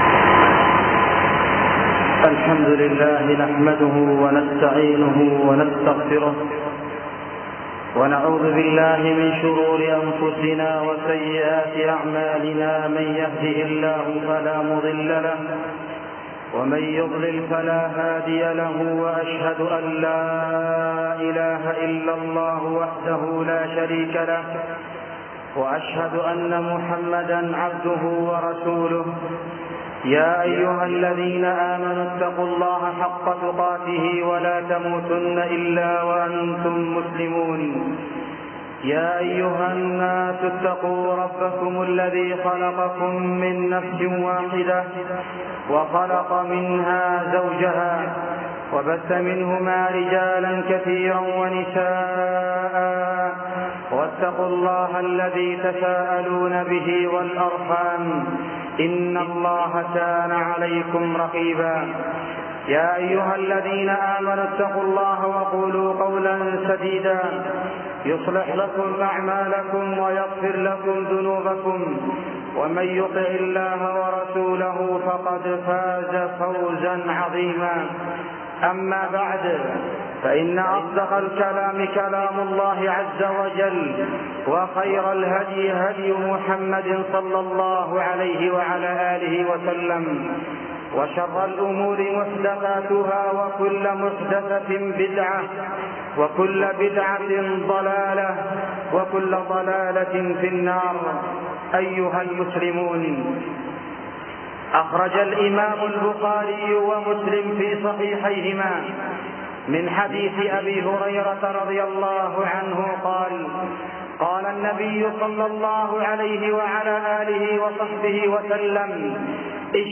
خطبة جمعة في دار الحديث ومركز السلام العلمي بالحديدة ــــ اليمن ـــ